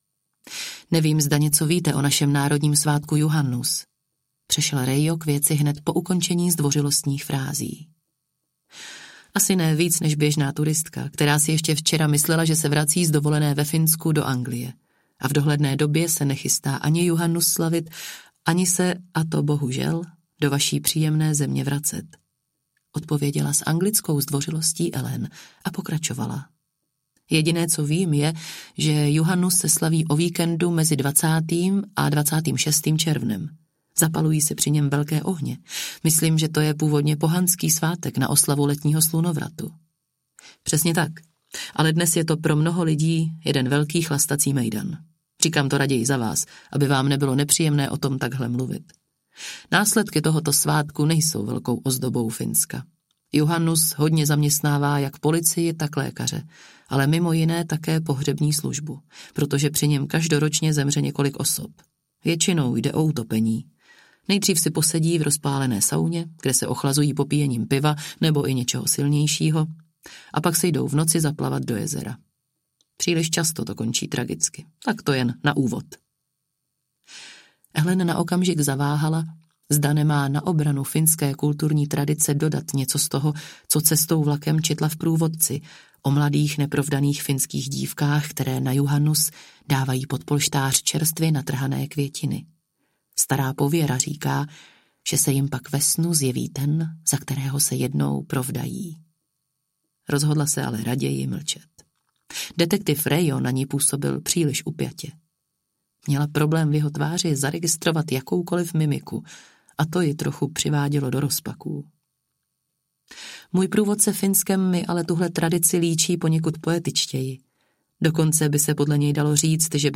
Brouk audiokniha
Ukázka z knihy